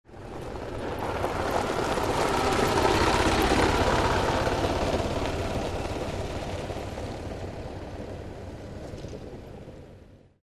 Звуки гольфа
Звук отъезжающей гольф кар